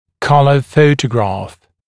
[‘kʌlə ‘fəutəgrɑːf] [‘калэ ‘фоутэгра:ф] цветная фотография (US color)